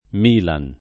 Milan [
m&lan] n. pr. m. — squadra di calcio di Milano (fondata nel 1899); propr. nome ingl. della città (pronunziato solo